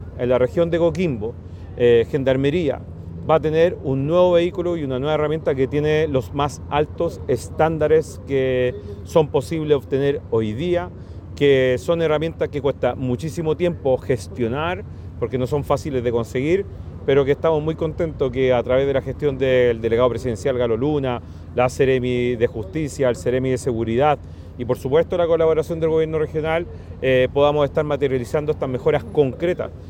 El subsecretario de Interior, Víctor Ramos destacó que